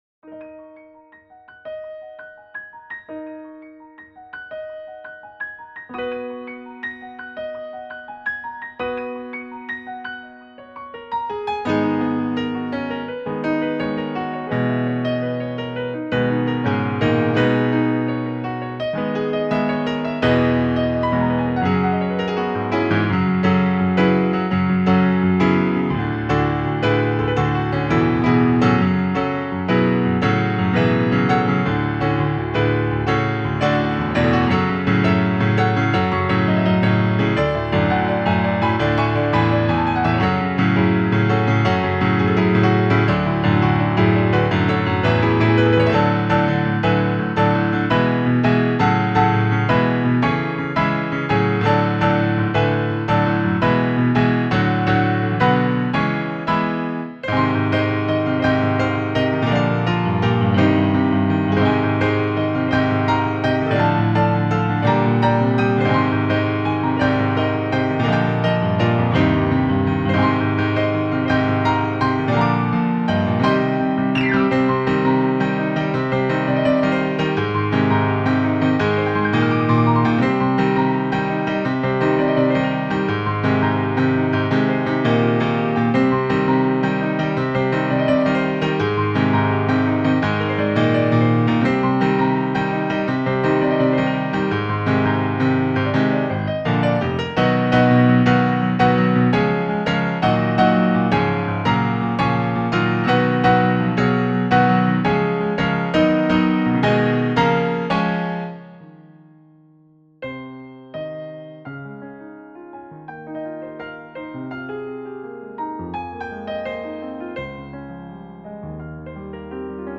PIANO
en forma de arreglo de piano.